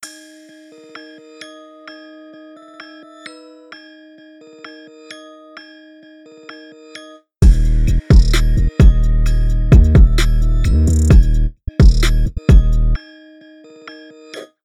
Alternative VCA Modes
Clicking on the LED to open the gate manually.
renegate-manual-opening.mp3